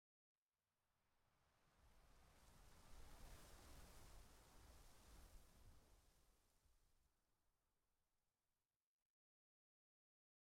1.21.5 / assets / minecraft / sounds / block / sand / wind5.ogg
wind5.ogg